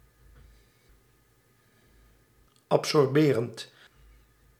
Ääntäminen
Ääntäminen France: IPA: [ap.sɔʁ.bɑ̃] Haettu sana löytyi näillä lähdekielillä: ranska Käännös Ääninäyte Adjektiivit 1. absorberend Muut/tuntemattomat 2. wateropnemend 3. absorptiemiddel {n} 4. opzuigend Suku: m .